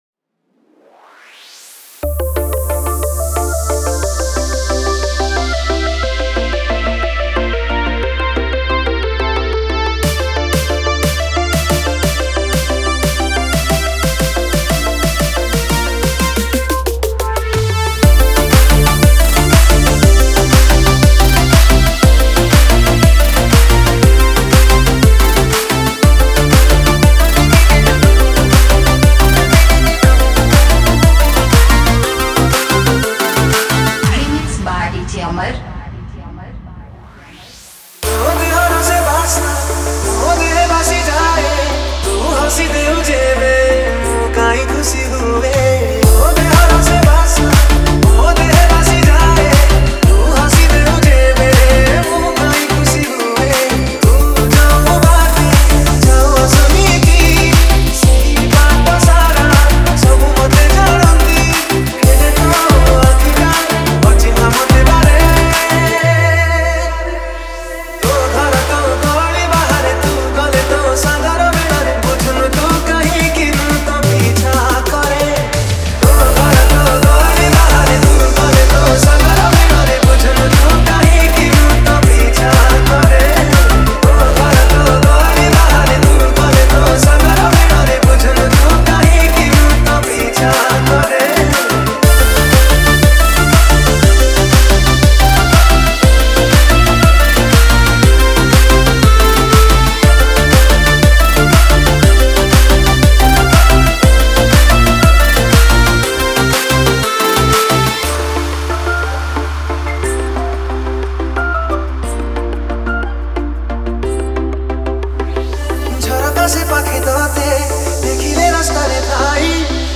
Romantic Love Dj Remix